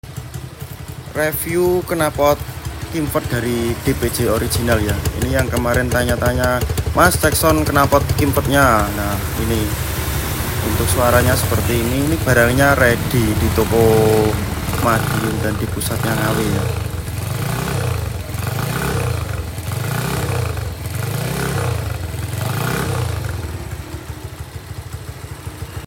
cek sound knalpot kimvet DPJ sound effects free download